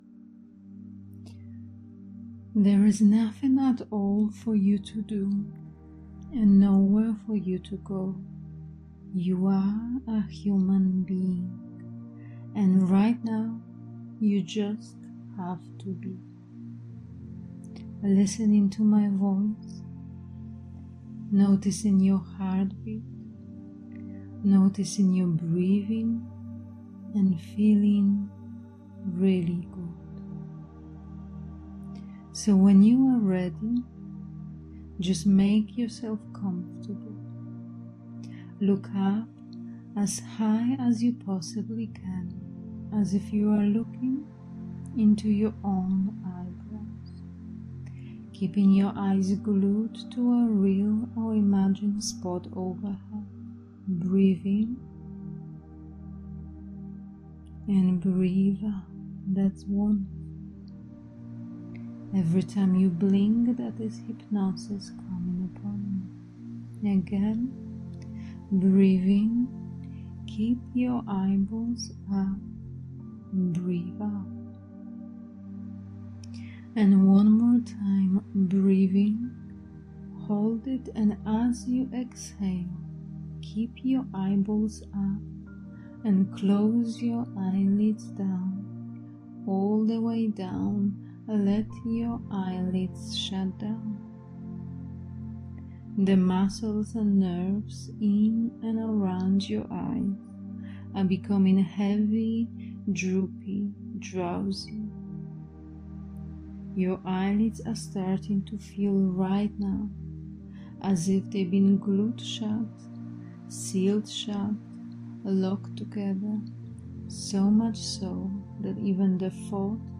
Self Healing Hypnosis
This powerful hypnosis recording uses one of the Rapid Transformational Therapy tools - Healing Vortex.
It is a deeply relaxing recording, that you should be listening with your eyes closed.